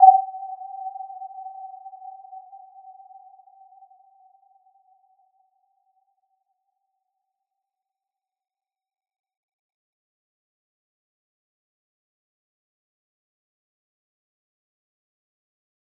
Warm-Bounce-G5-f.wav